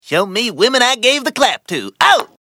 Quagmire Clap Sound Effect Free Download